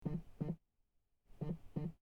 Звуки вибрации iPhone
Сердцебиение